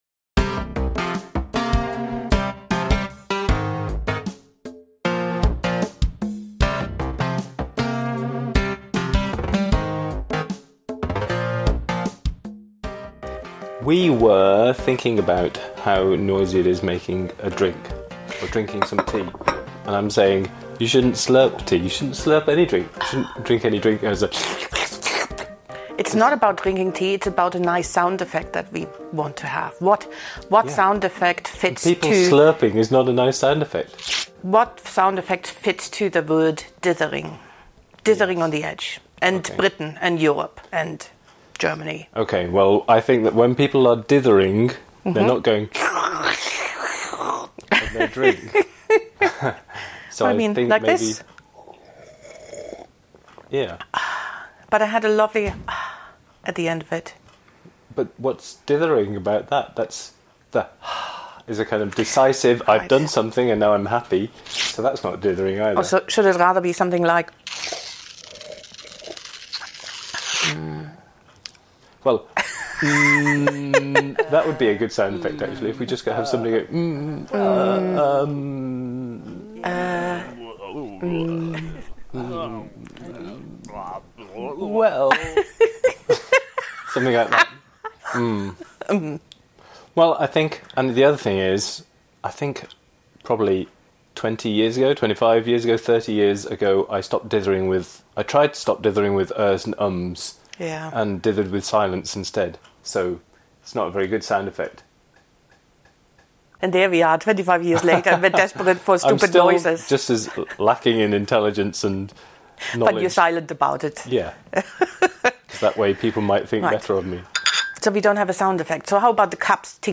Nothing particularly illuminating here, just us trying to decide on sound effects for the podcast.